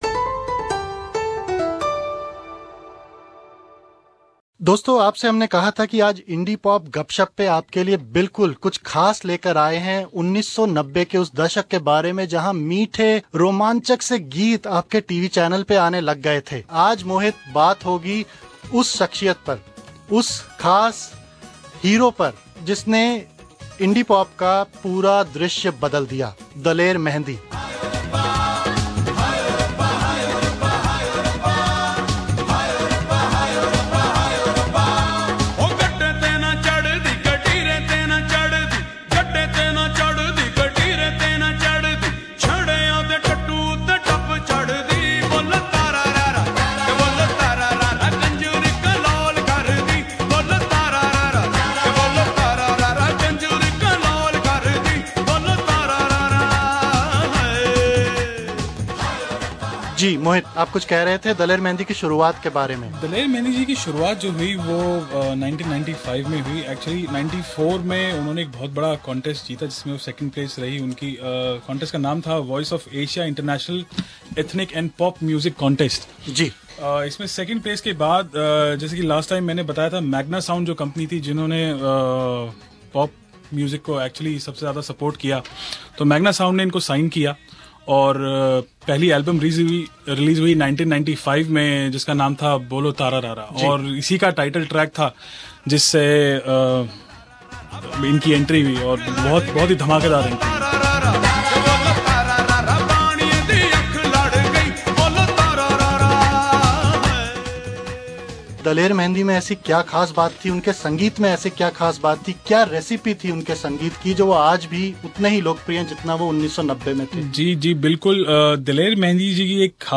SBS Hindi